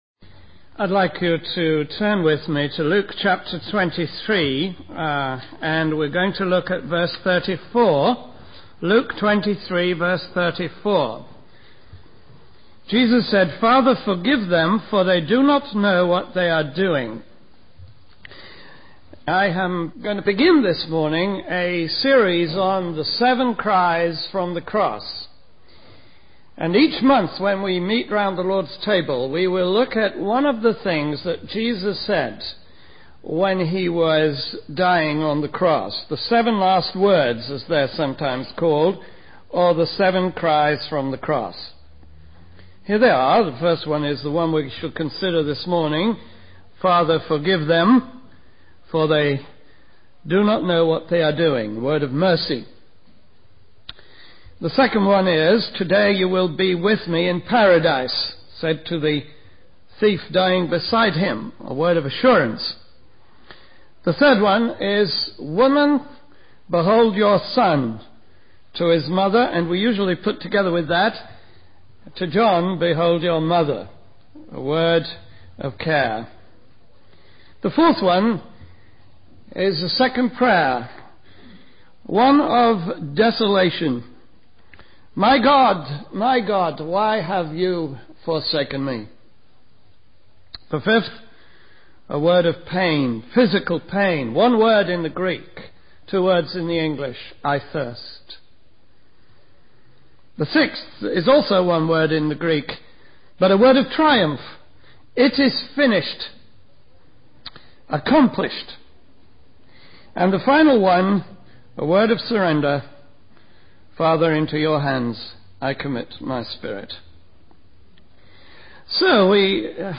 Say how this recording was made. This series of meditations on the seven utterances made by our Lord Jesus in the hours when he was hanging on the Cross were originally given at Communion services, and subsequently produced as booklets.